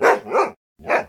bdog_idle_0.ogg